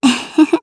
Gremory-Vox_Happy3_jp.wav